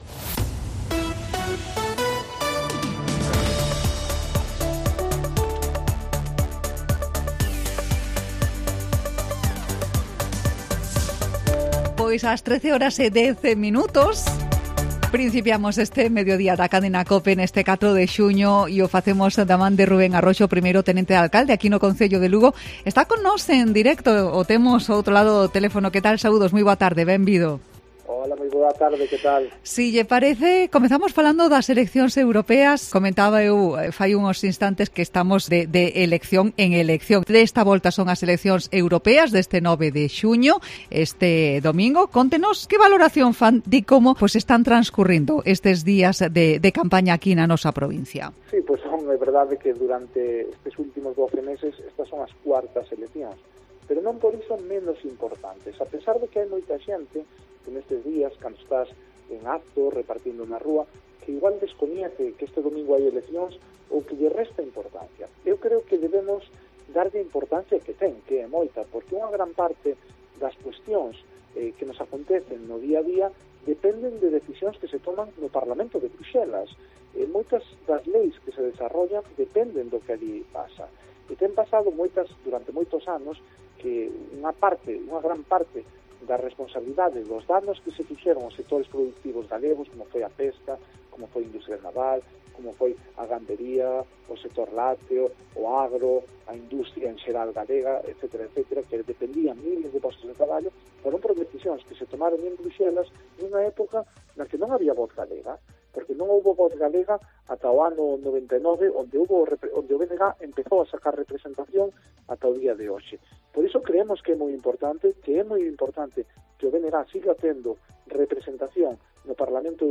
Entrevista a Rubén Arroxo en COPE Lugo (04/06/2024)